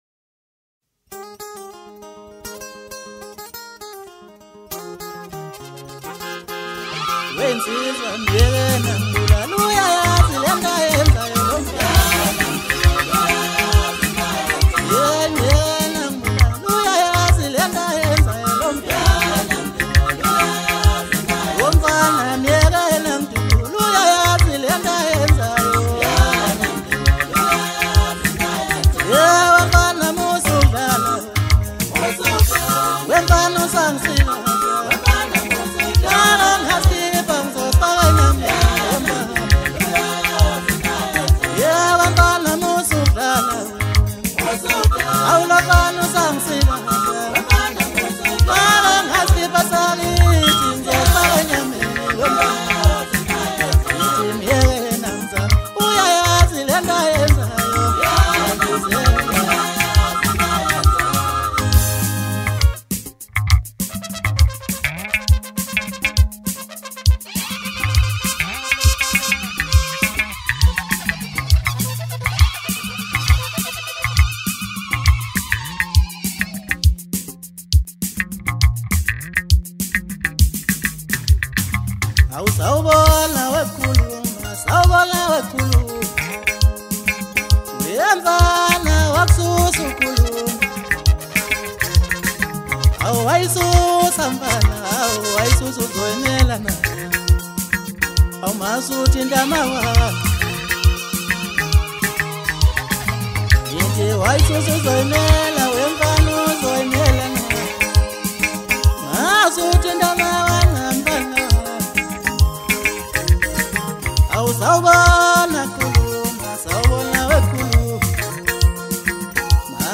Maskandi Songs